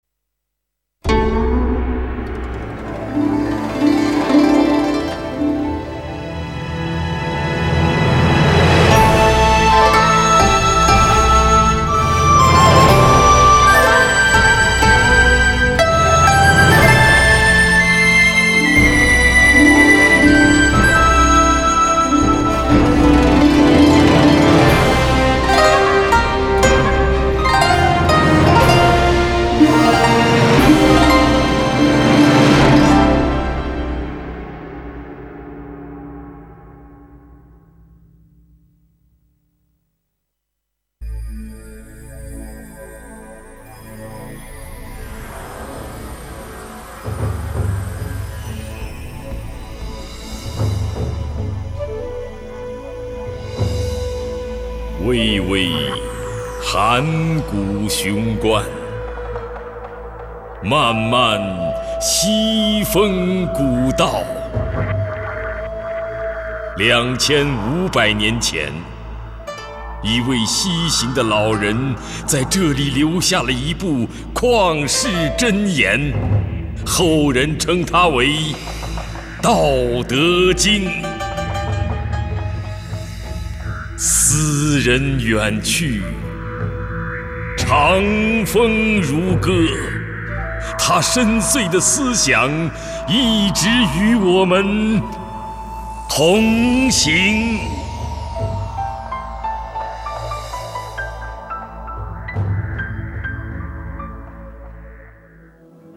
用喜闻乐见的歌唱和朗诵相结合的艺术形式